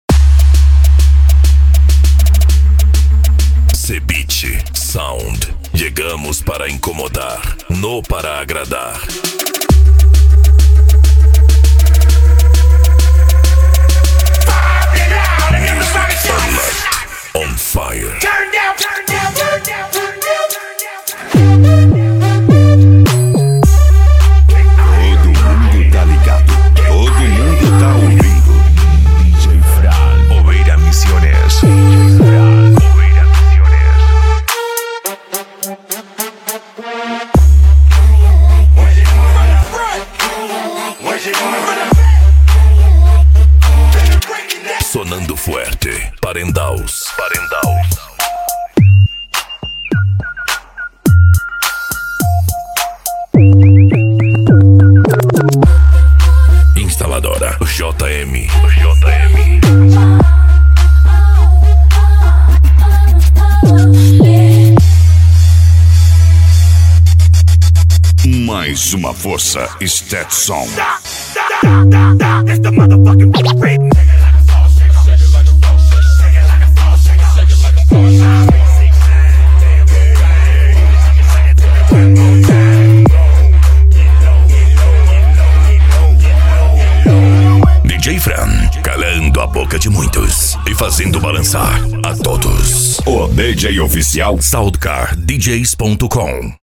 Remix
Racha De Som
Bass